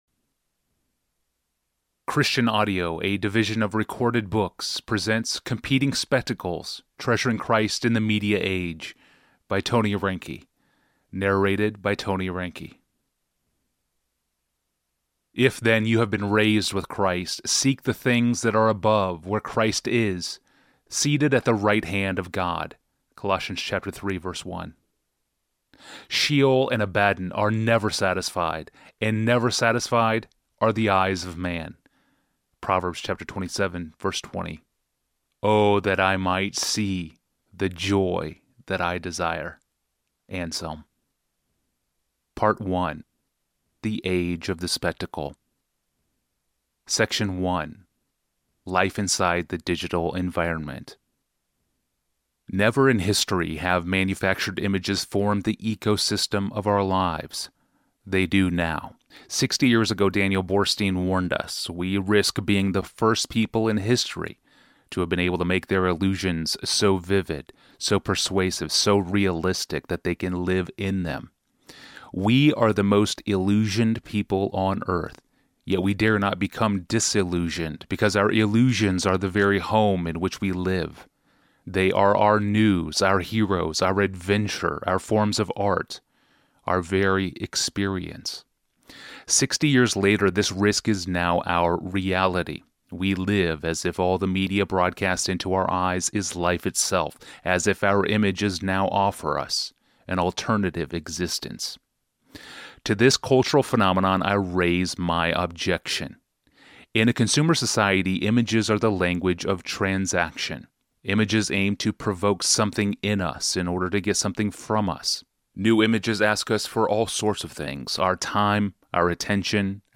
Competing Spectacles: Treasuring Christ in the Media Age Audiobook
3.33 Hrs. – Unabridged